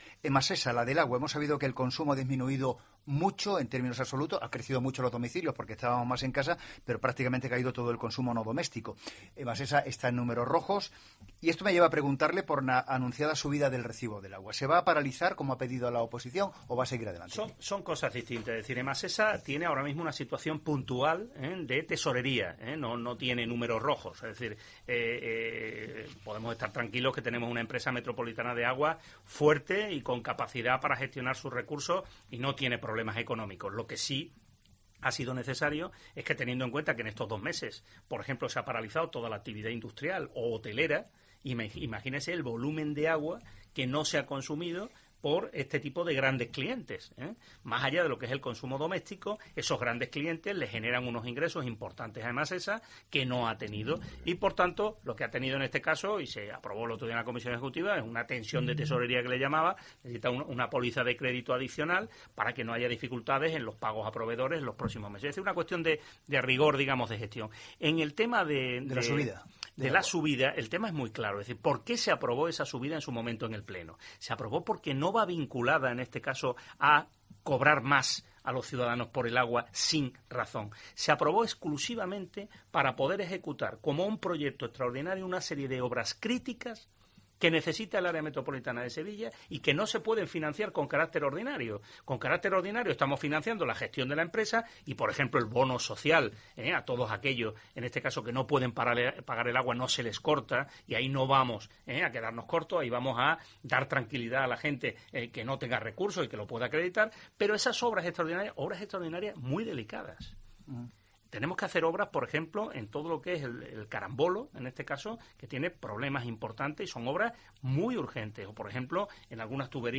El alcalde de Sevilla, Juan Espadas, ha asegurado en los micrófonos de COPE Sevilla que la subida en el recibo del agua aprobada por Emasesa, la Empresa Metropolitana de Aguas de Sevilla, no se va a paralizar, aún en la actual situación económica que deja en la ciudad la pandemia del coronavirus, pues, según señala, es necesaria para llevar a cabo “una serie de obras críticas que necesita el área metropolitana de Sevilla y que no se pueden financiar con carácter ordinario”.